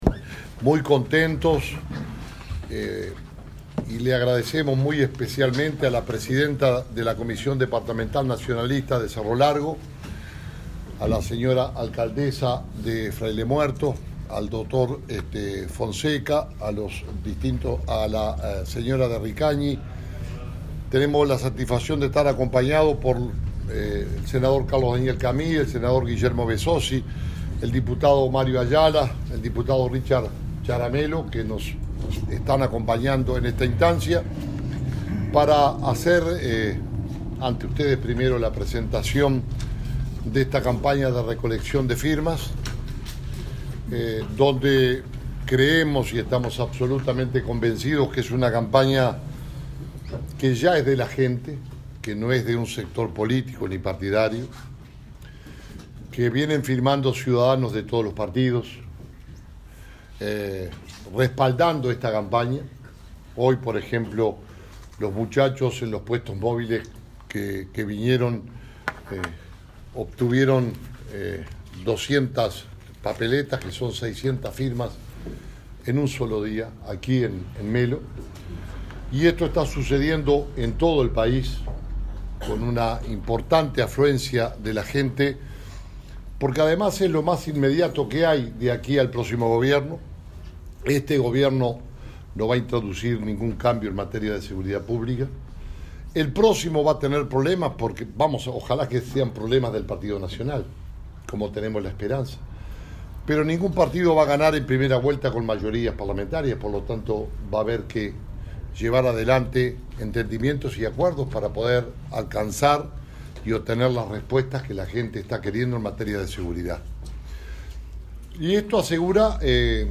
conferencia-melo.mp3